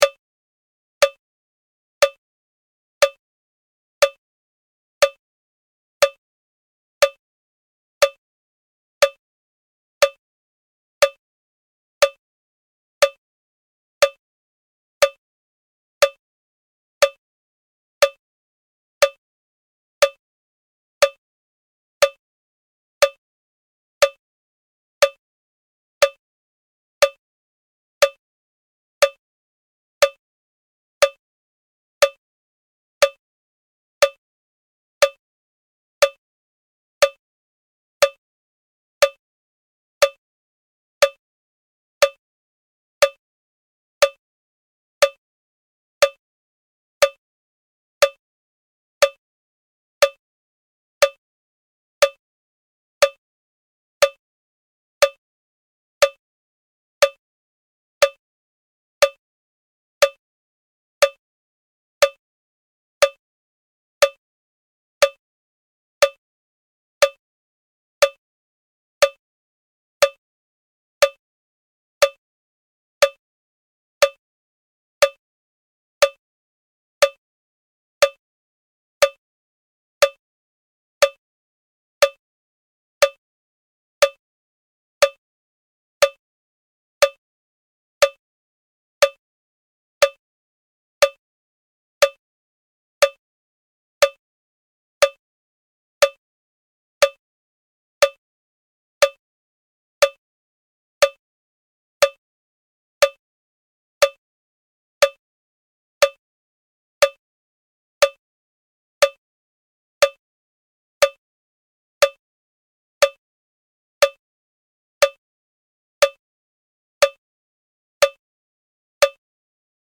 test-60bpm.ogg